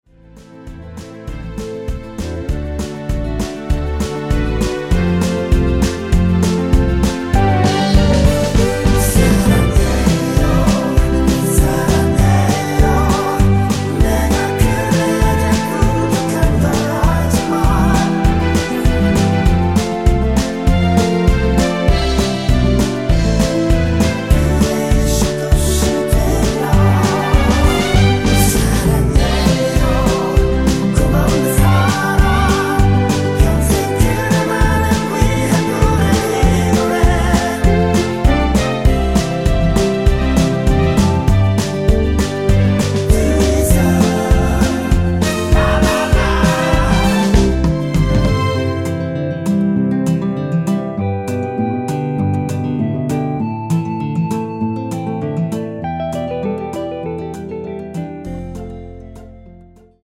전주가 길어서 8마디로 편곡 하였으며
원키(1절+후렴)으로 진행되는 멜로디와 코러스 포함된 MR입니다.
앞부분30초, 뒷부분30초씩 편집해서 올려 드리고 있습니다.
중간에 음이 끈어지고 다시 나오는 이유는